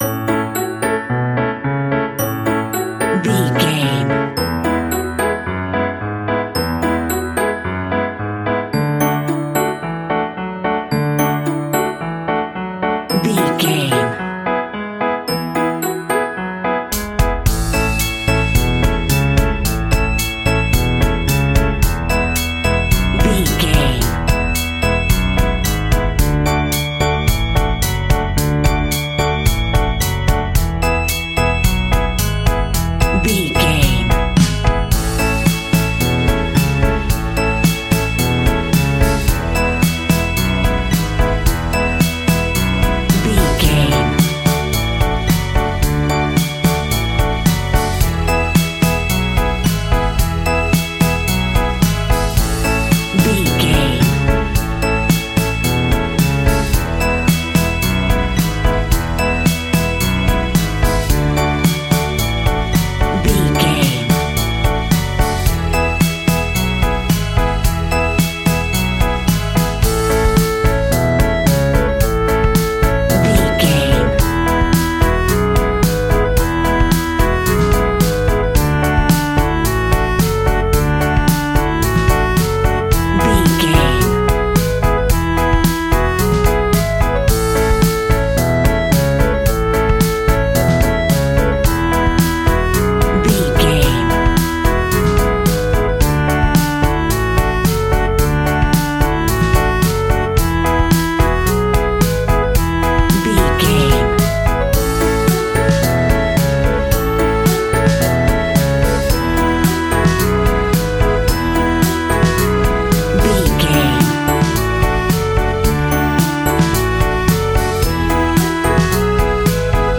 Uplifting
Ionian/Major
kids instrumentals
fun
childlike
cute
happy
kids piano